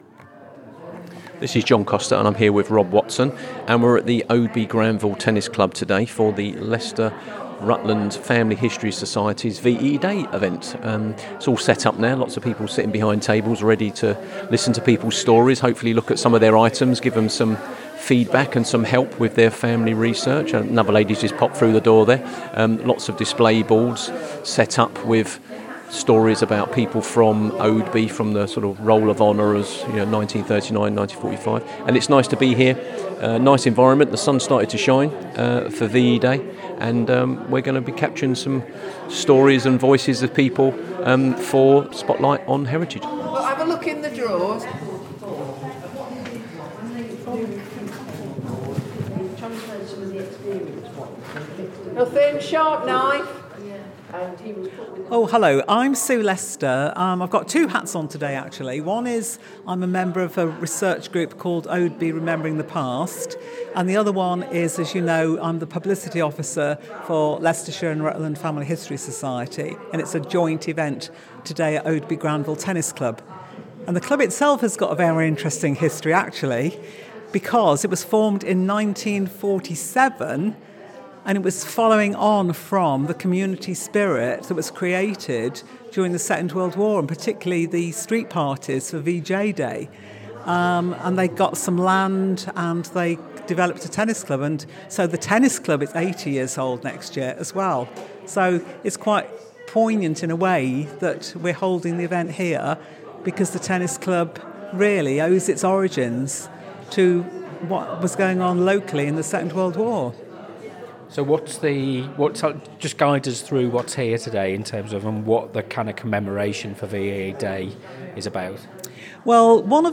From wartime street parties to stories of service, loss, and return, the episode captures a rich tapestry of voices.